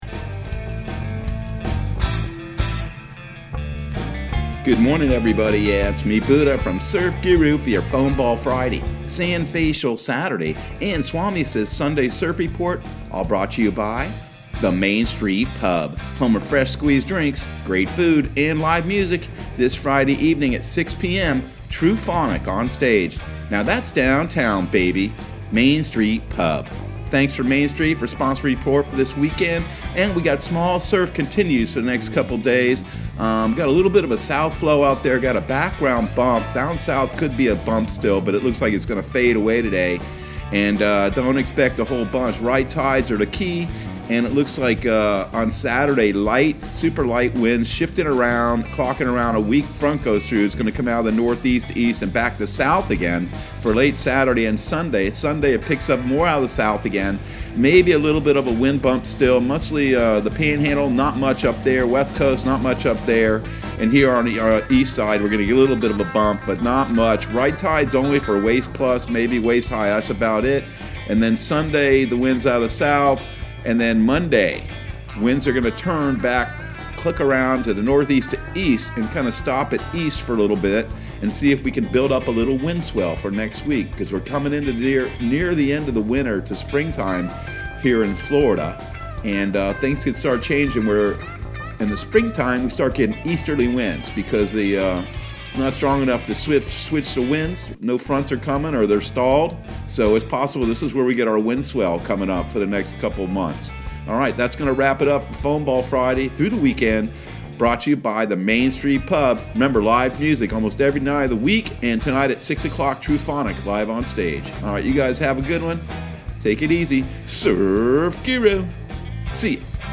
Surf Guru Surf Report and Forecast 02/05/2021 Audio surf report and surf forecast on February 05 for Central Florida and the Southeast.